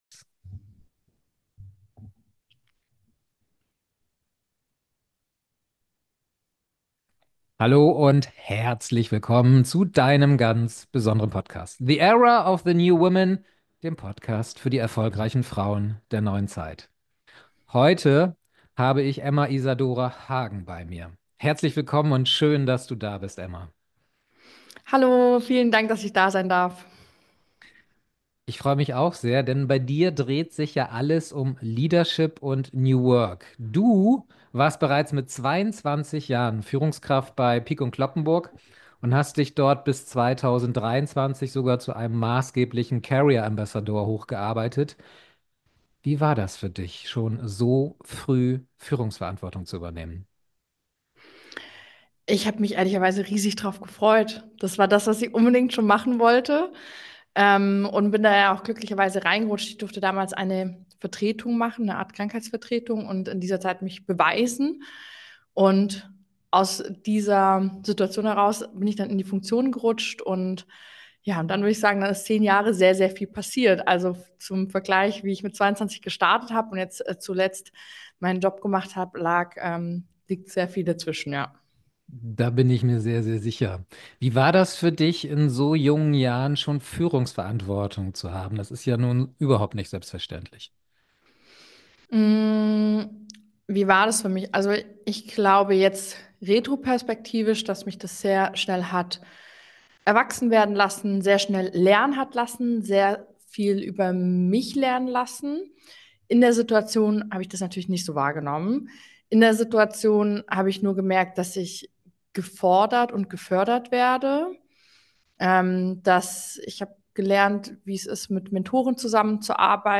Ein Gespräch über Mut, Menschlichkeit und weibliche Klarheit – für Frauen, die anders führe...